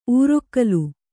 ♪ ūrokkalu